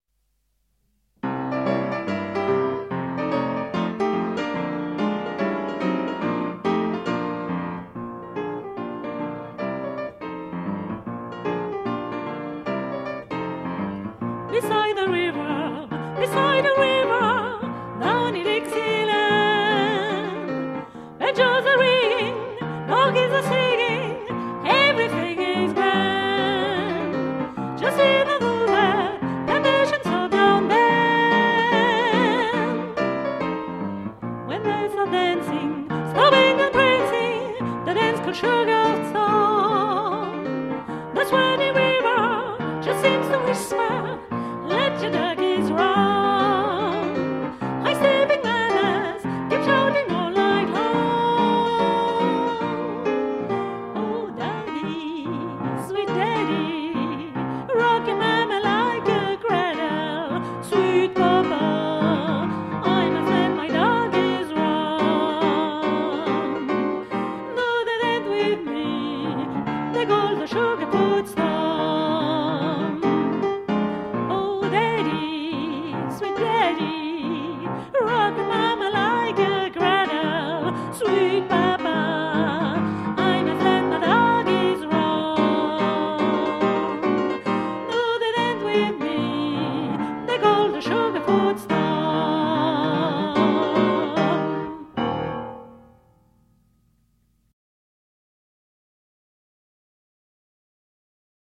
- Vieux thèmes de jazz
piano